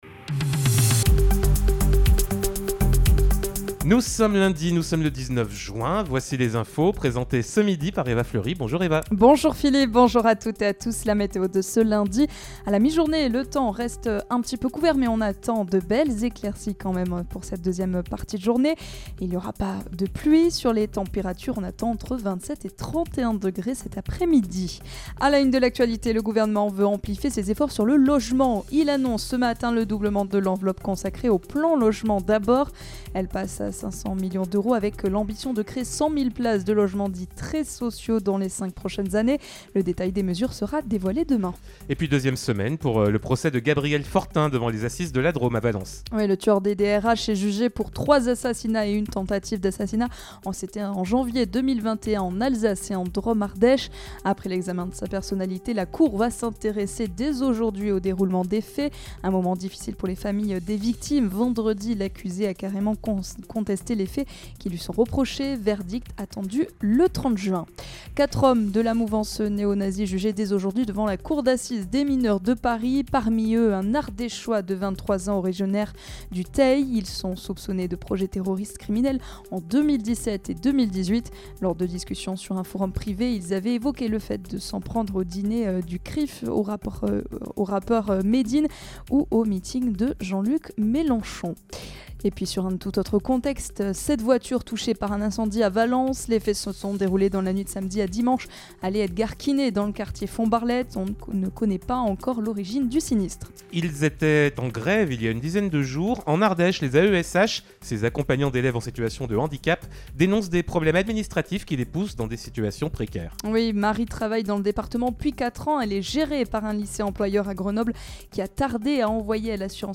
Lundi 19 juin : Le journal de 12h